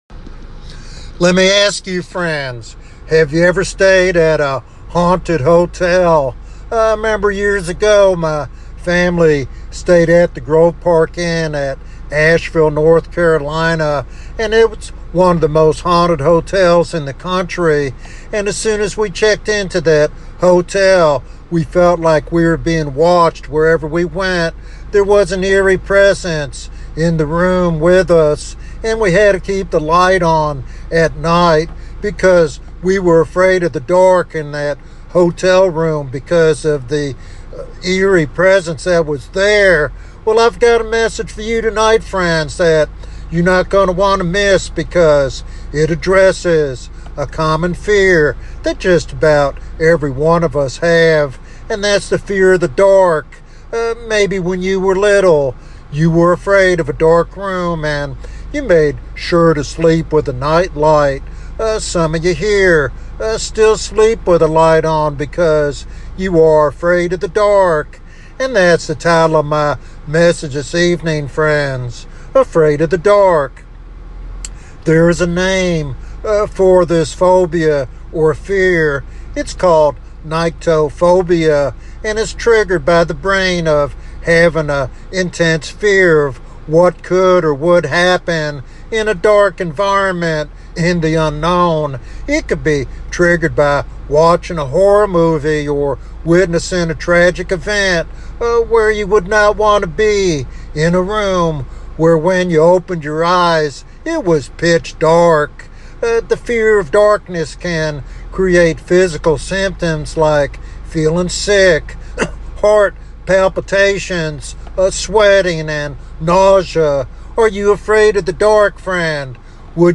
This evangelistic message challenges believers and seekers alike to consider their eternal destiny and embrace the light of Christ.